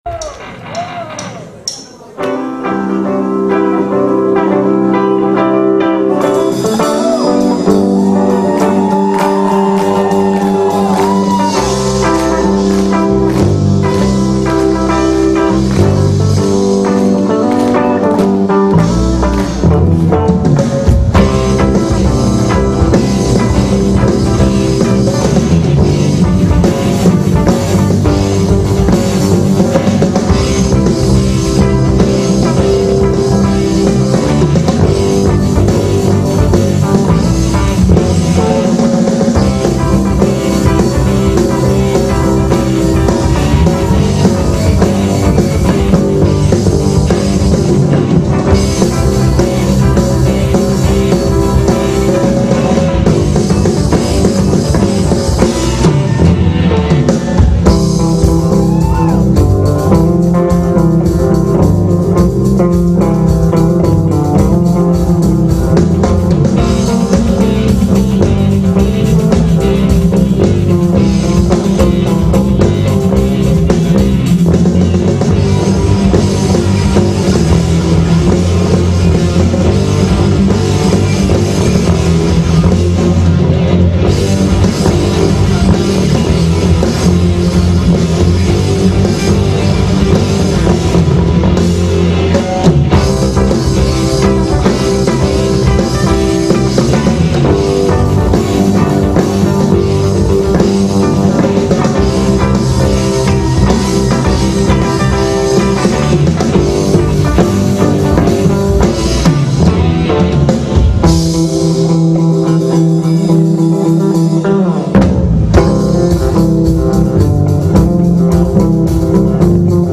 Приветствуем Вас на сайте нашей рок-группы!